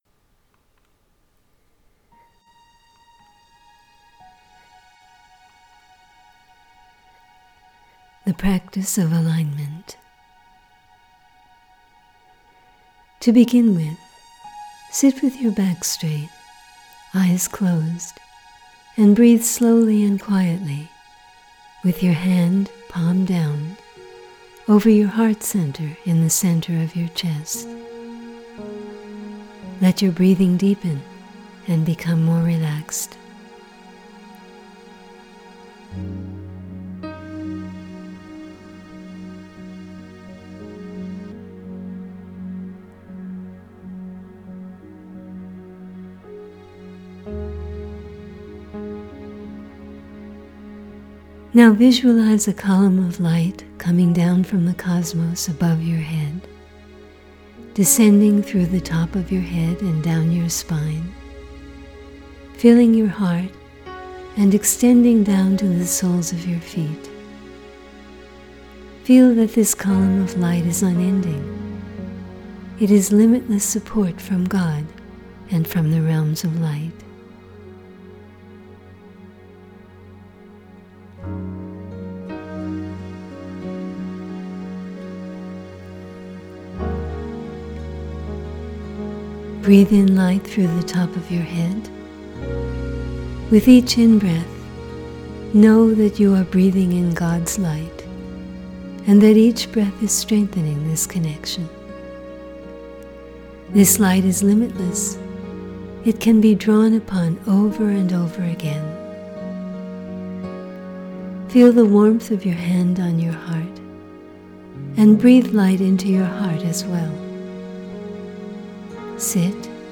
Practice of Alignment Recorded Meditation
Listen with music        ---     Watch video